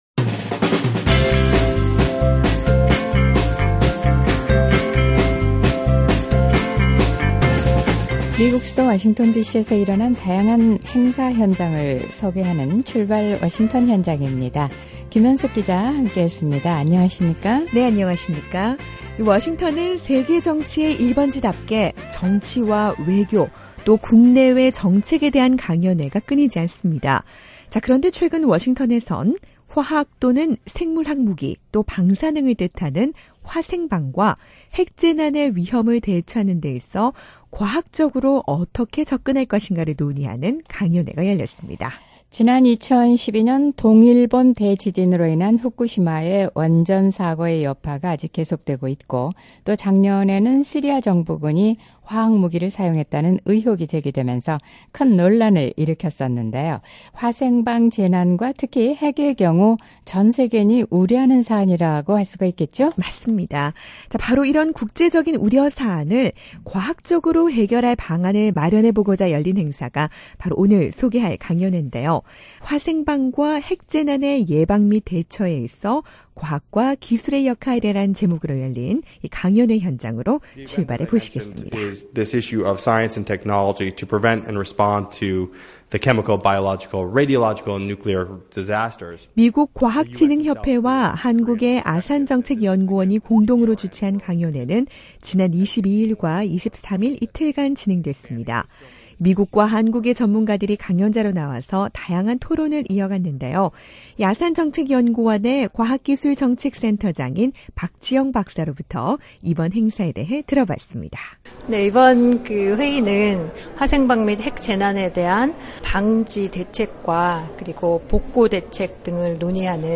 이런 화생방과 핵 재난을 방지하고 또 복구대책을 논의하기 위해 미국과 한국의 전문가들이 한자리에 모였습니다. 미국 과학진흥협회(AAAS)와 한국의 아산정책연구원이 공동으로 주최한 ‘화생방과 핵 재난 예방 및 대처에서 과학과 기술의 역할’ 이라는 제목의 강연회 현장으로 출발해봅니다.